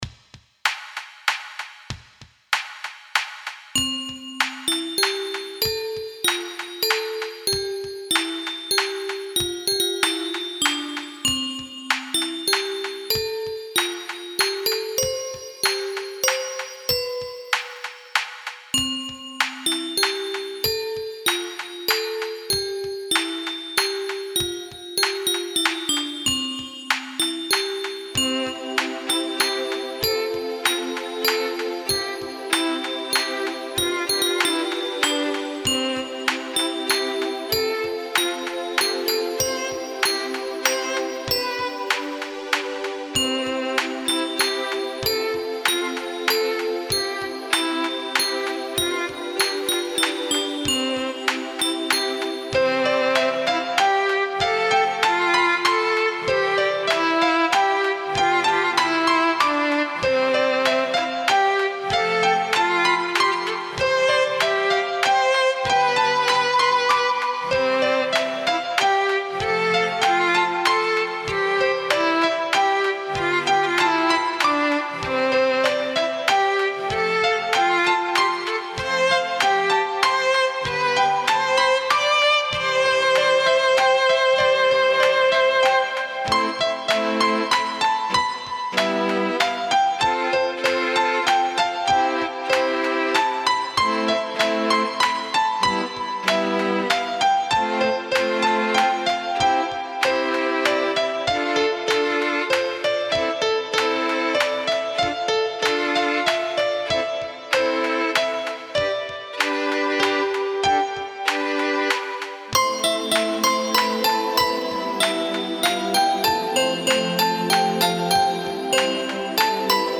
Музыка для детей Категория: Написание музыки